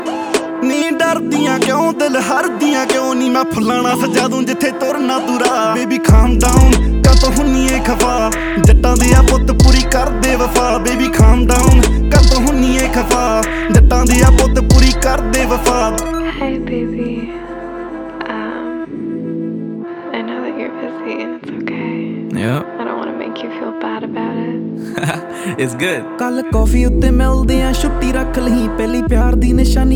Жанр: Иностранный рэп и хип-хоп / R&b / Соул / Рэп и хип-хоп